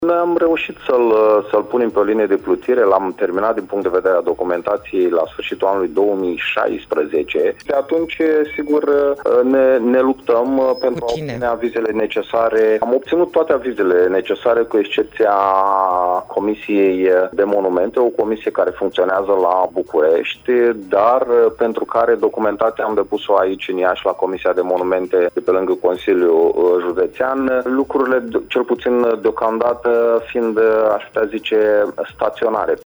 Viceprimarul municipiului Iași, Radu Botez a declarat în această dimineață că în calea Planului Urbanistic General mai stă doar un punct de vedere consultativ.